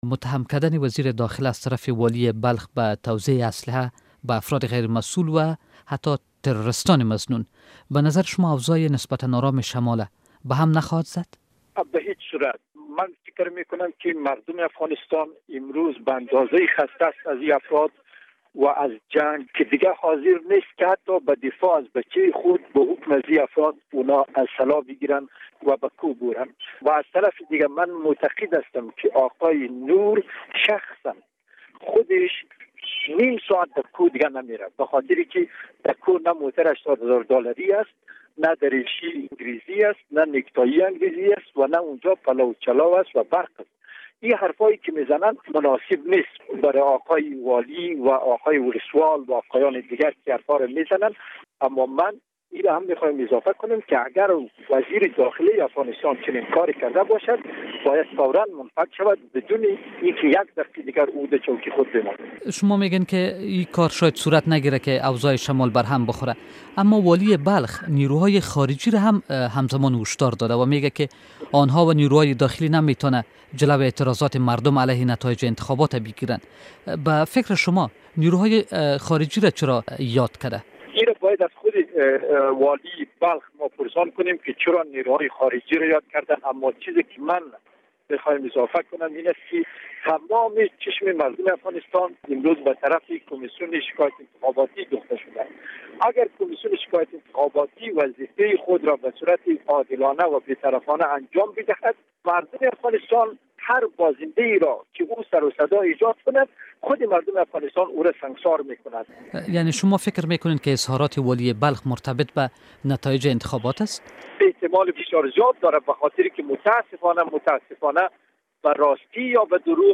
مصاحبه با رمضان بشردوست